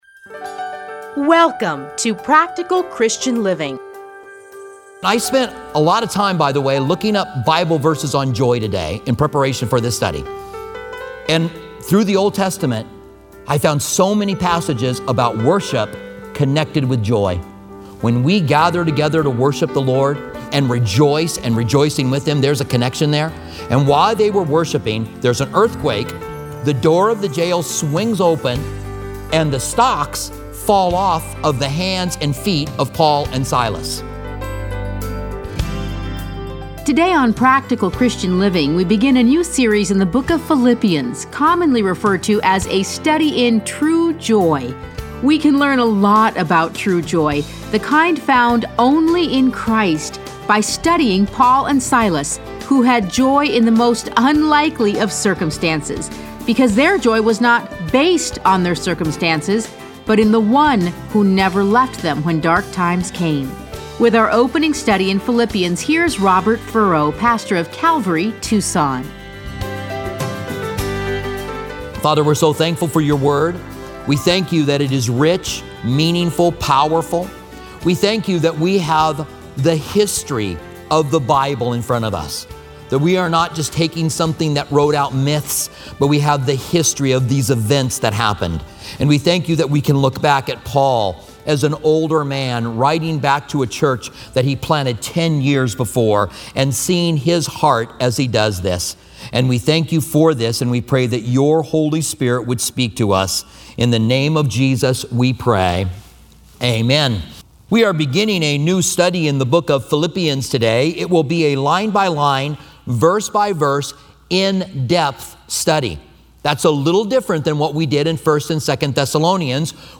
Listen to a teaching from Philippians 1:1-30 Playlists A Study in Philippians Download Audio